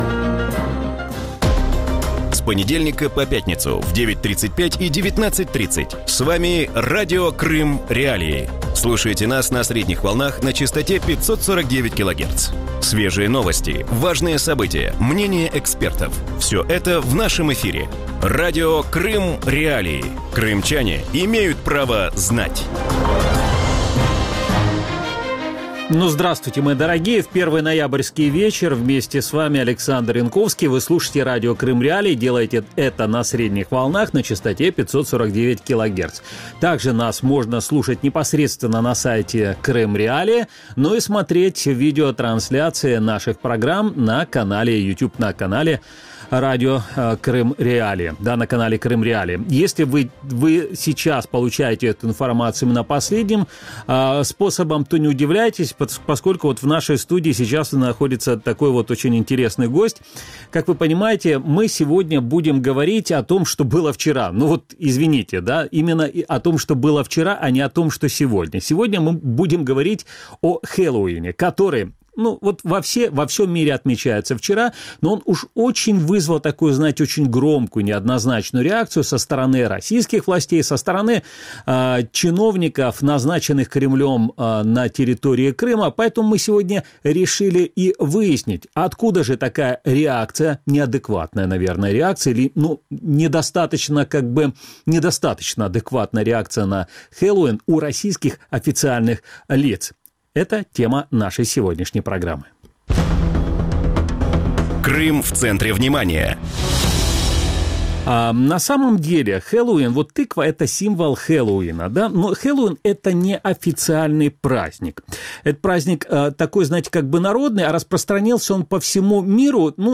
В вечернем эфире Радио Крым.Реалии обсуждают неприязнь российских чиновников Крыма к Хэллоуину и западной культуре. Почему на полуострове боятся западных ценностей, когда в России закончится этап традиционализма и чем грозит слишком долгое следование по «особому пути»?